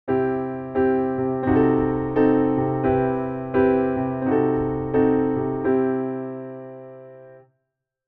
Auxiliary Tonic Diminished